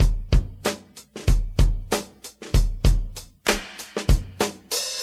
96 Bpm Breakbeat D# Key.wav
Free drum beat - kick tuned to the D# note. Loudest frequency: 1052Hz
.WAV .MP3 .OGG 0:00 / 0:05 Type Wav Duration 0:05 Size 865,99 KB Samplerate 44100 Hz Bitdepth 16 Channels Stereo Free drum beat - kick tuned to the D# note.
96-bpm-breakbeat-d-sharp-key-iRS.ogg